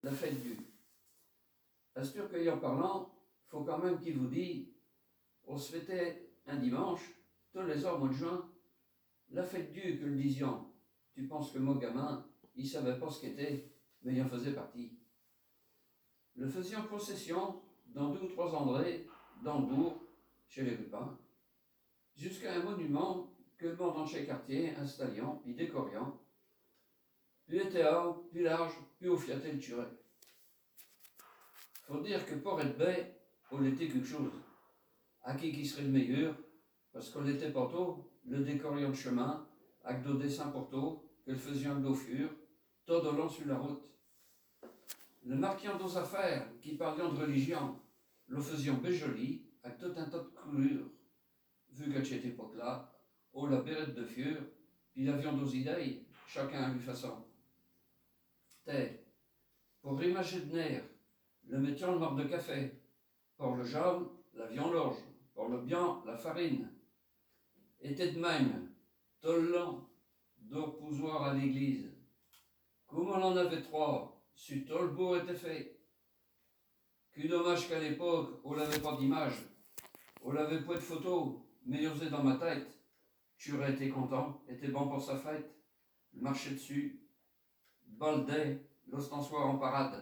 Genre poésie
Catégorie Récit